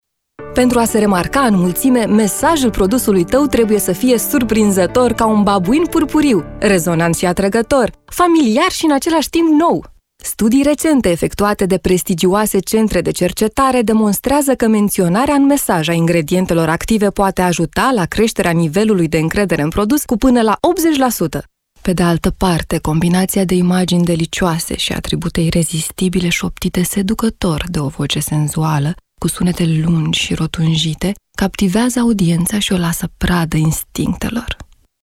罗马尼亚语女4_外语_小语种_ge.mp3